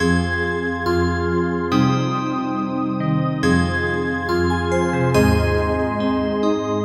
VZ钟声 Bpm 140
描述：铃铛
标签： 140 bpm Trap Loops Bells Loops 1.15 MB wav Key : Unknown
声道立体声